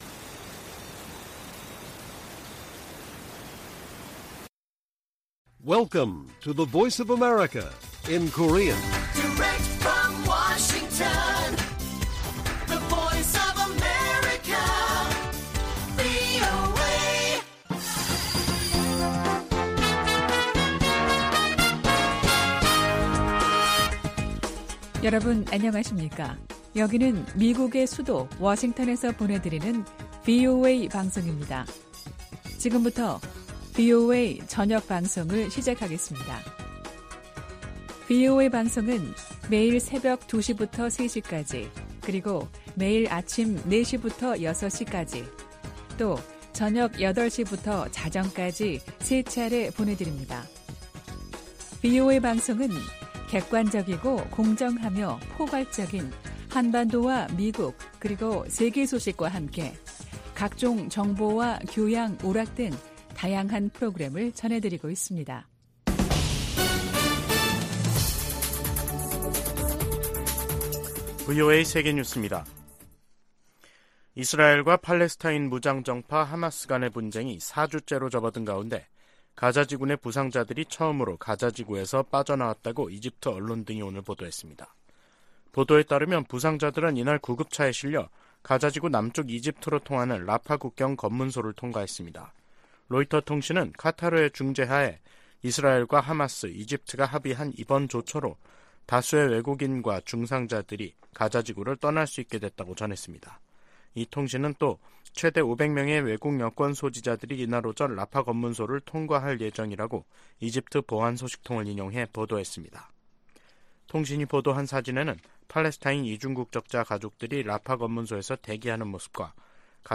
VOA 한국어 간판 뉴스 프로그램 '뉴스 투데이', 2023년 11월 1일 1부 방송입니다. 미 국방부는 한국 정부가 9.19 남북군사합의의 효력 정지를 검토 중이라고 밝힌 데 대해 북한 위협에 대응해 한국과 계속 협력할 것이라고 밝혔습니다. 한국 국가정보원은 북한이 러시아의 기술자문을 받으면서 3차 군사정찰위성 발사 막바지 준비를 하고 있다고 밝혔습니다. 미 국토안보부장관은 북한 등 적성국 위협이 진화하고 있다고 말했습니다.